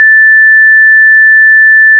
\(4\cdot 440\;\mathrm{Hz}=1760\;\mathrm{Hz}\)
Audio abspielen Ton \(a^\prime\) + 2 Oktaven = \(a^{\prime\prime\prime}\)
Sinus-1760Hz-2s.ogg